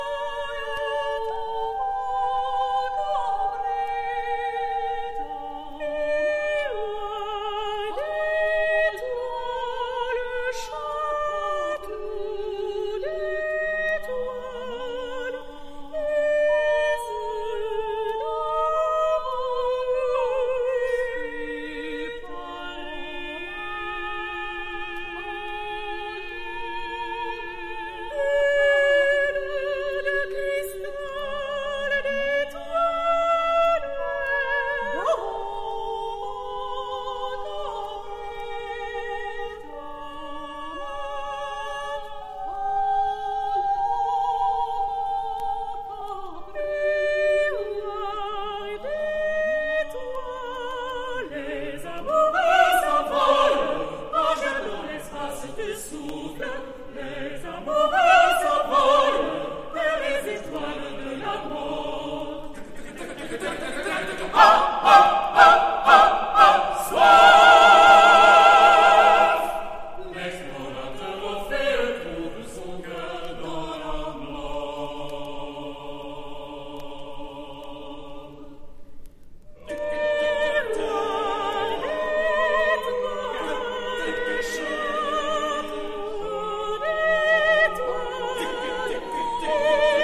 現代音楽